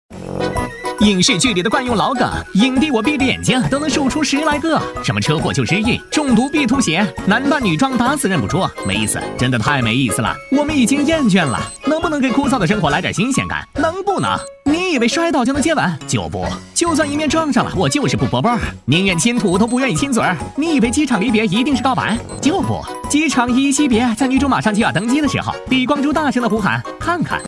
飞碟调侃类男318号
年轻时尚 飞碟说配音